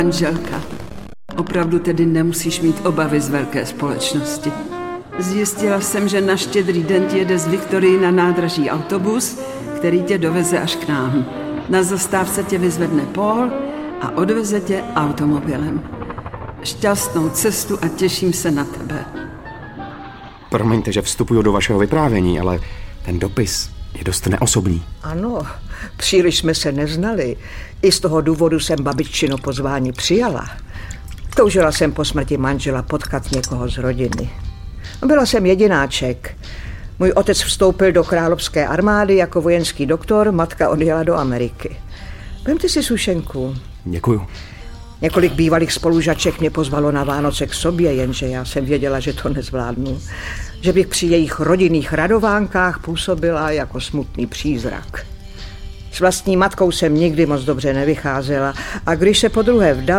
Český rozhlas natočil dramatický seriál Zahalte jí tvář (2014), četbu z knihy Pachuť smrti (2020) a dramatizaci povídky Vánoční vražda (2020).